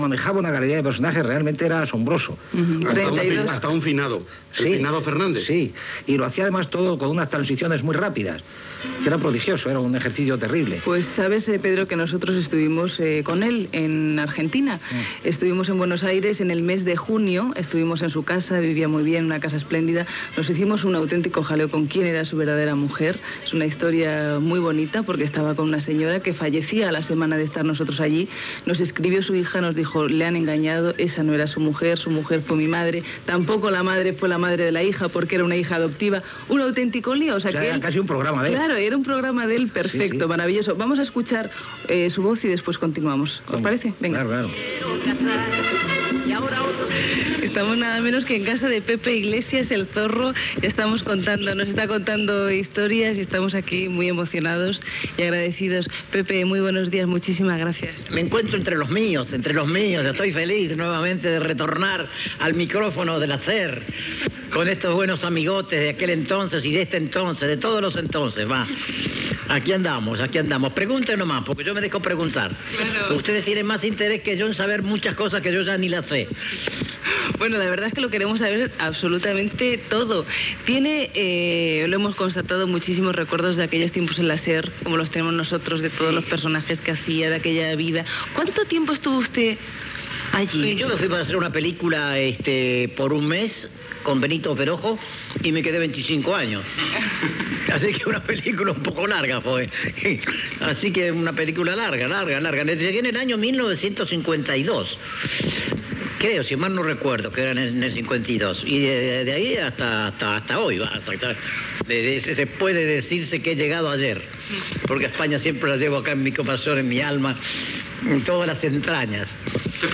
Entrevista a Pepe Iglesias, "el Zorro".
Entreteniment